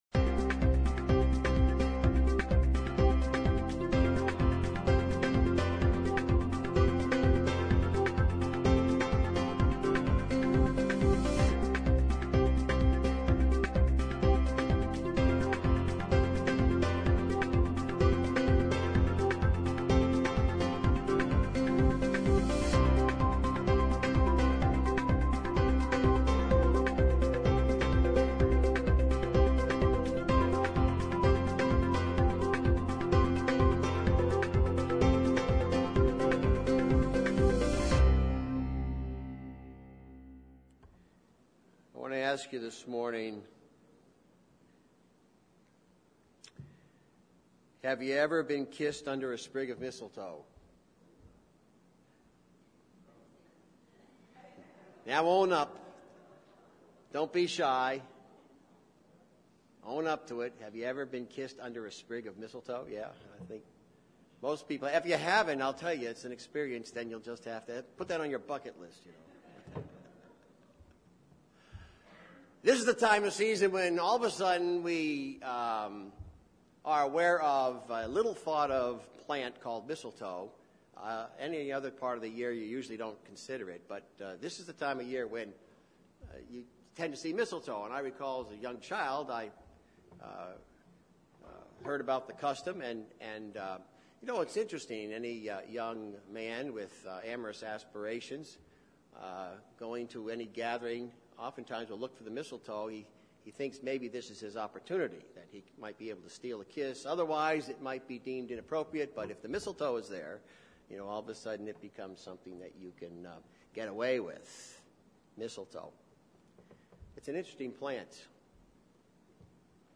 Christmas, Mistletoe, and Christ – Trinity Church | Derry, NH 03038